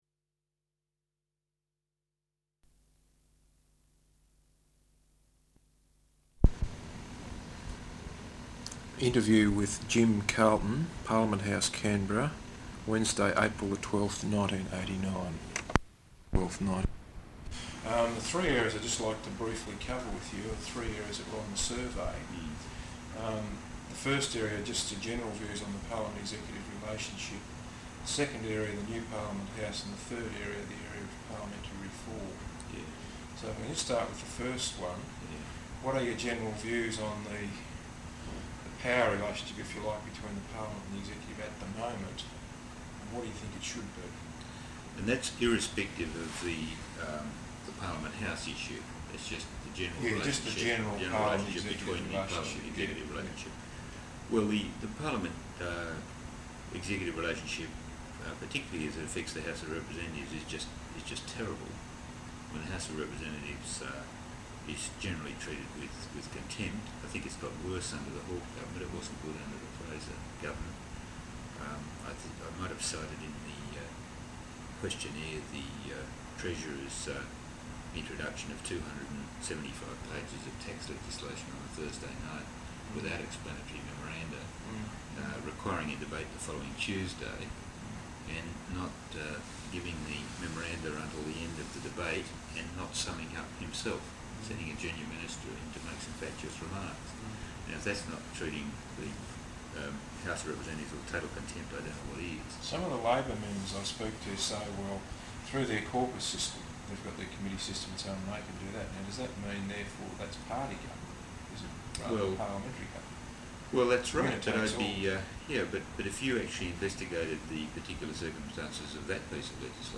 Interview with the Hon Jim Carlton MP, Parliament House, Canberra April 12th, 1989.